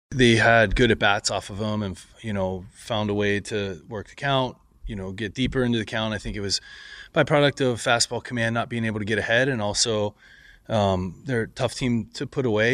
Manager Donnie Kelly says Skenes didn’t have his best stuff.